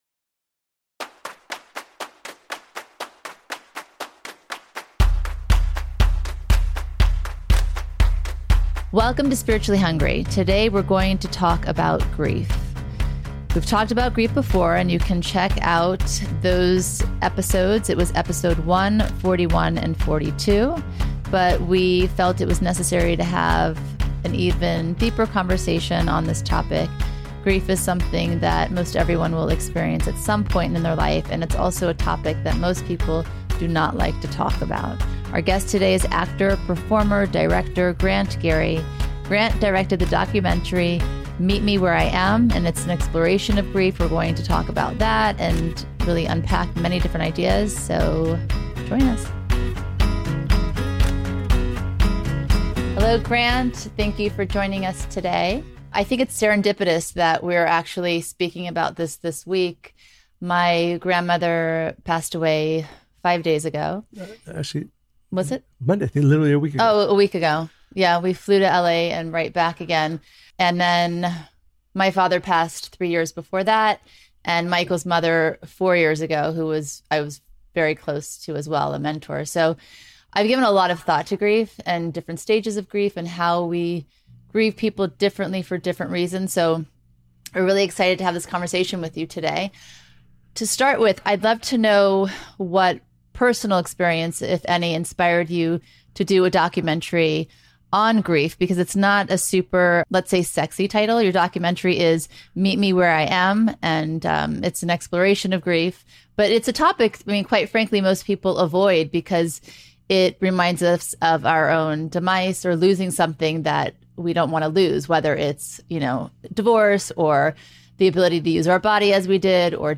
Join them for a down-to-earth conversation about the big life questions that spark your curiosity the most.